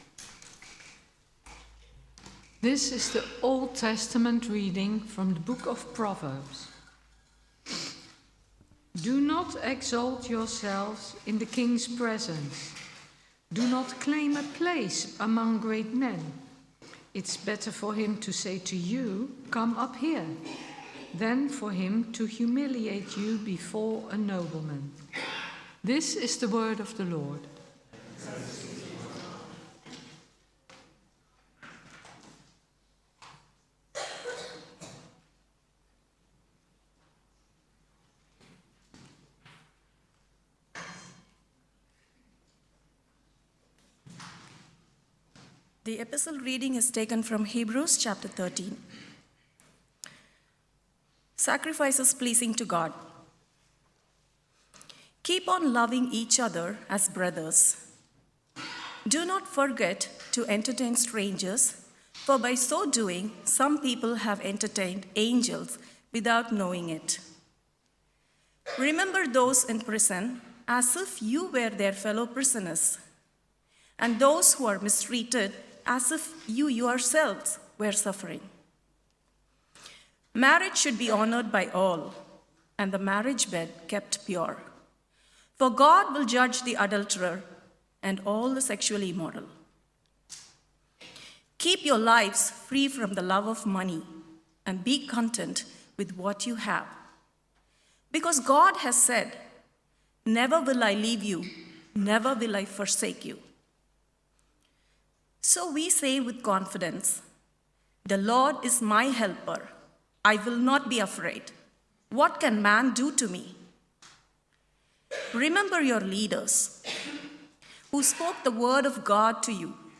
Sermon – August 31, 2025